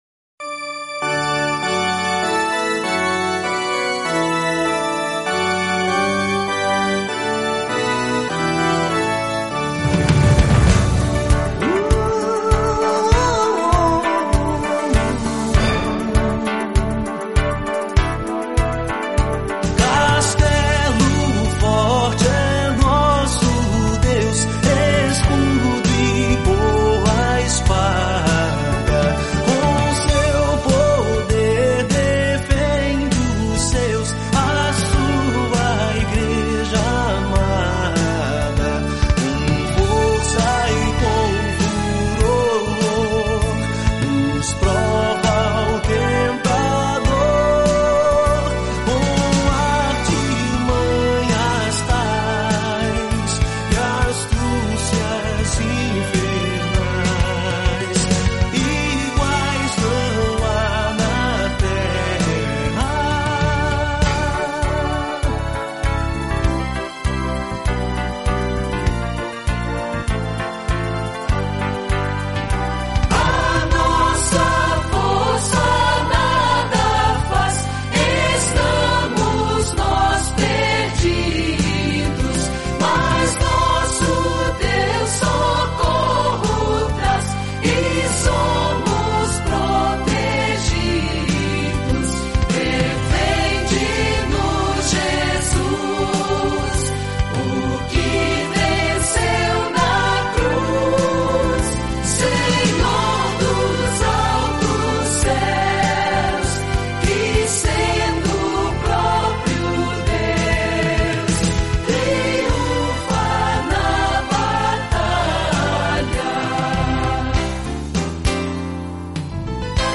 Ocorreu, no entanto, que nesta última semana estive, agora na Rádio Zé FM, para participar do programa Mensagem de Paz, das Igrejas Presbiterianas de Americana.